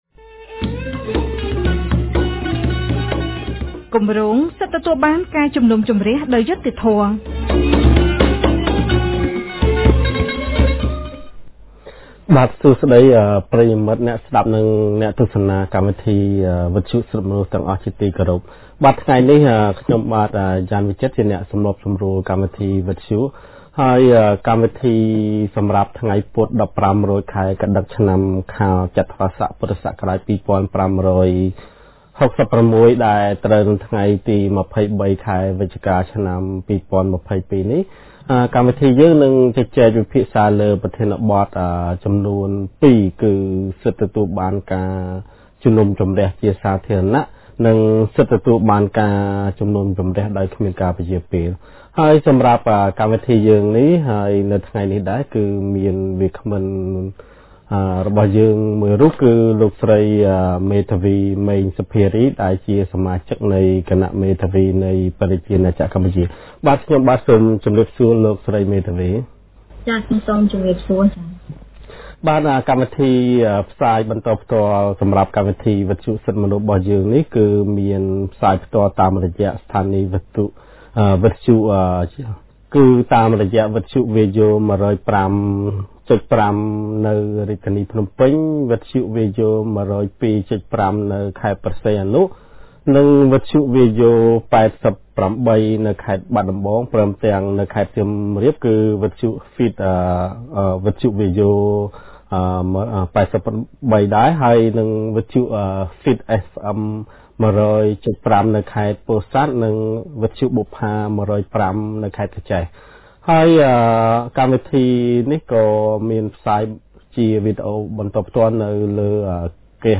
នៅថ្ងៃពុធ ទី២៣ ខែវិច្ឆិកា ឆ្នាំ២០២២ គម្រាងសិទ្ធិទទួលបានការជំនុំជម្រះដោយយុត្តិធម៌នៃមជ្ឈមណ្ឌលសិទ្ធិមនុស្សកម្ពុជា បានរៀបចំកម្មវិធីវិទ្យុក្រោមប្រធានបទស្តីពី សិទ្ធិទទួលបានការជំនុំជម្រះជាសាធារណៈ និងសិទ្ធិទទួលបានការជំនុំជម្រះដោយគ្មានការពន្យារពេល។